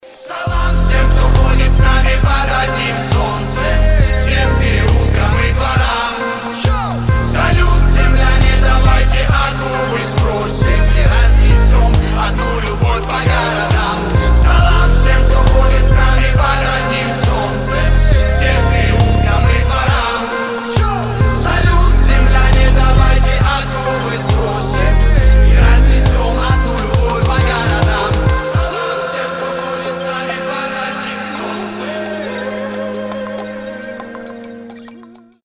• Качество: 320, Stereo
позитивные
регги
добрые